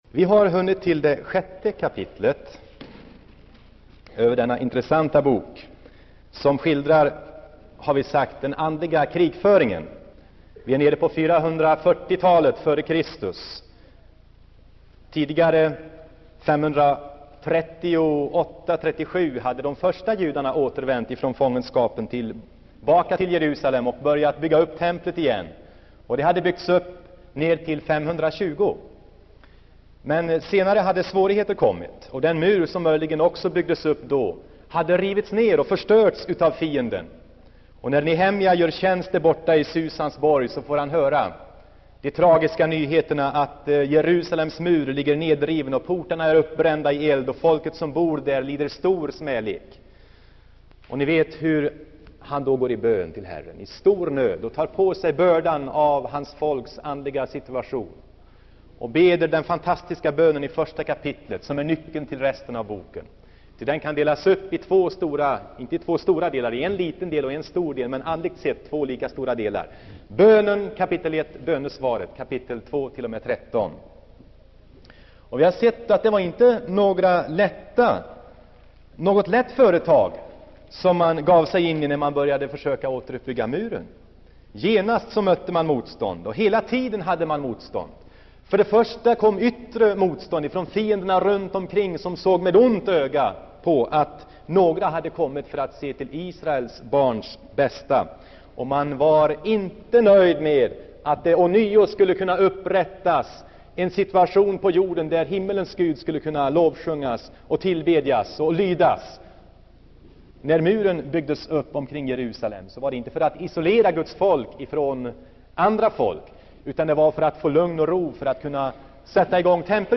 Inspelad i Saronkyrkan, Göteborg 1977-09-11.